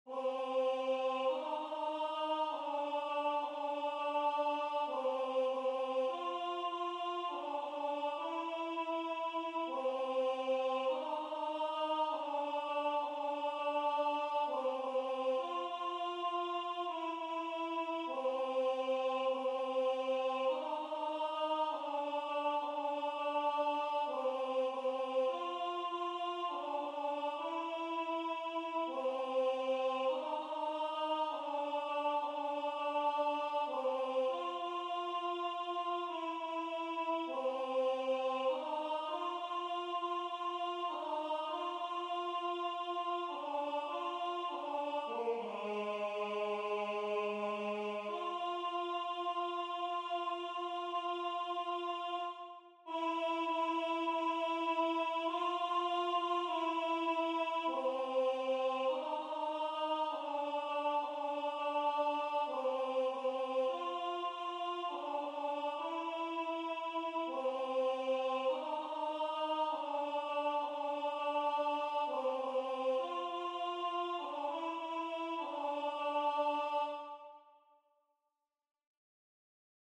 MP3 rendu voix synth.